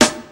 • Clean Rap Acoustic Snare Sample A# Key 395.wav
Royality free steel snare drum sample tuned to the A# note. Loudest frequency: 2701Hz
clean-rap-acoustic-snare-sample-a-sharp-key-395-8Ek.wav